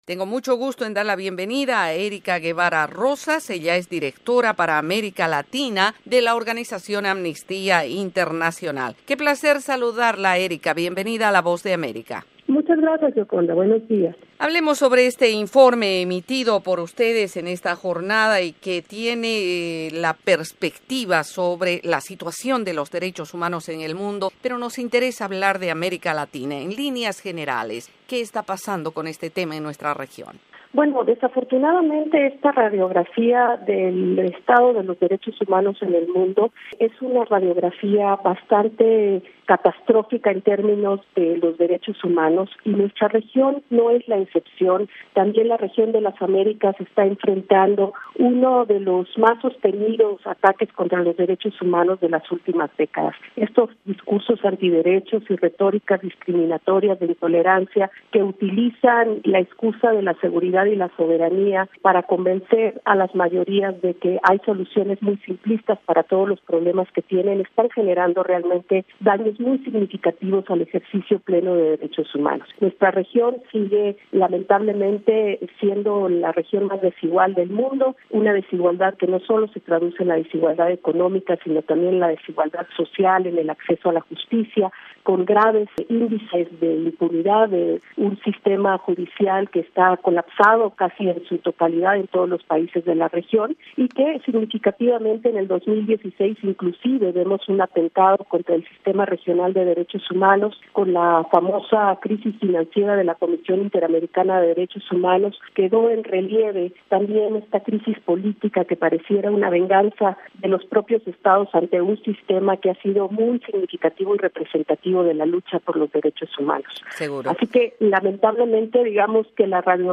destaca en entrevista con la Voz de América detalles del informe 2016 - 2017 sobre los derechos humanos incluyendo un análisis sobre Estados Unidos, México y Venezuela, entre otros.